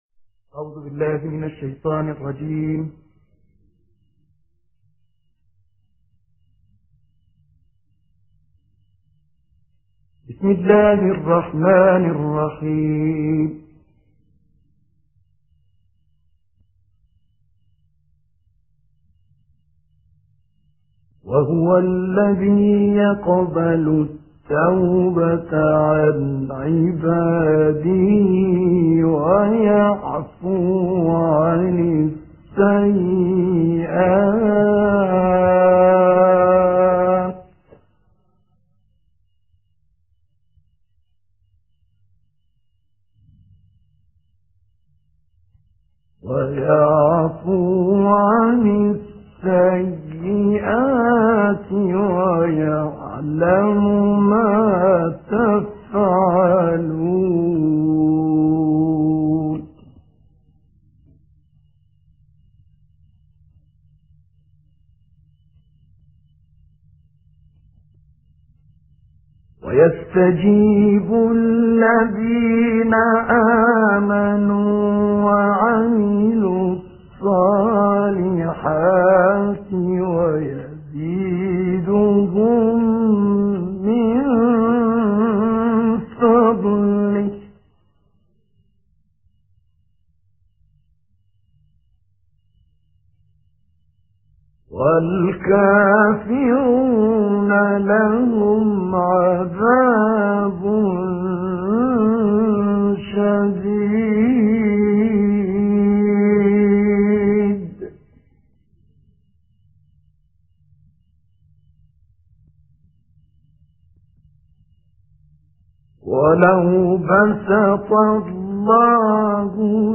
تلاوة رائعة بصوت القارئ المصري الراحل الشيخ طه الفشني
اسلاميات_الكوثر: تلاوة رائعة للآية الـ25 لغاية الـ51 من سورة "الشورى" المباركة، وذلك بصوت القارئ المصري الشهير الراحل "الشيخ طه حسن مرسي الفشني".